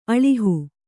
♪ aḷihu